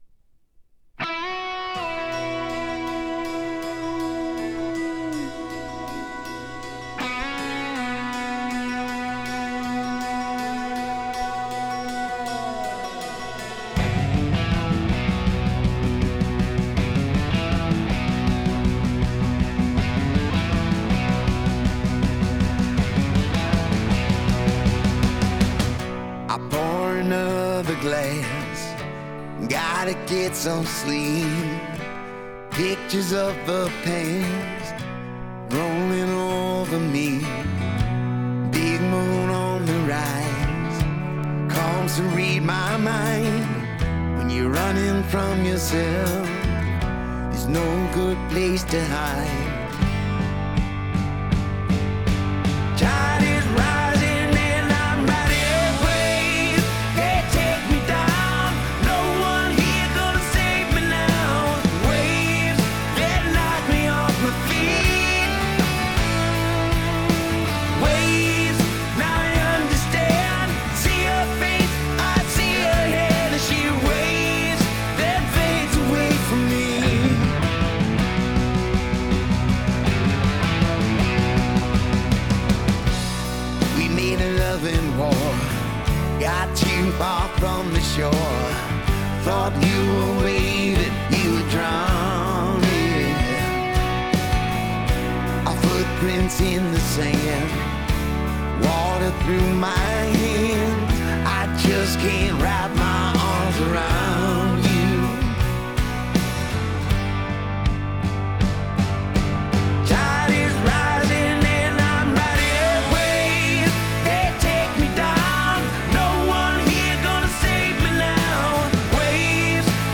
类型：Hard Rock 媒体：2xLP